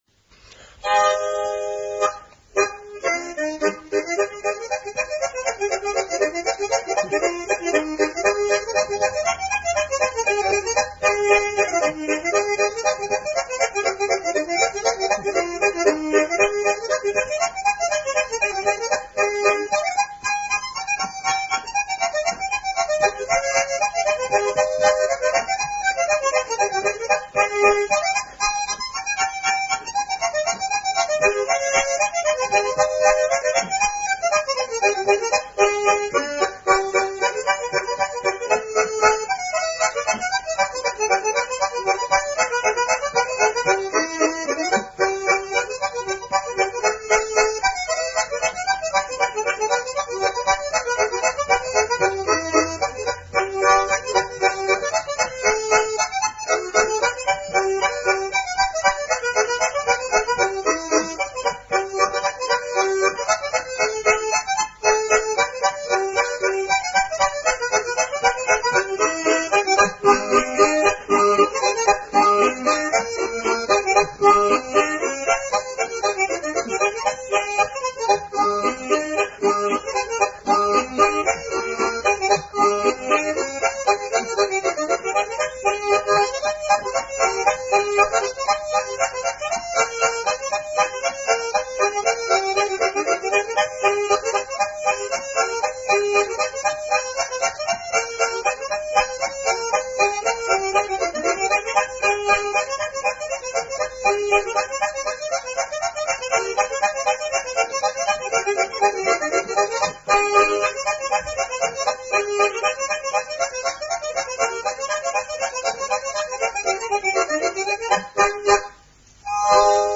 Scots Moothie